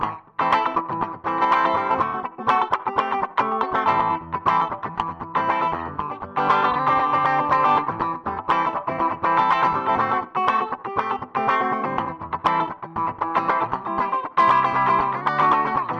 标签： 120 bpm Funk Loops Guitar Electric Loops 2.69 MB wav Key : G Ableton Live
声道立体声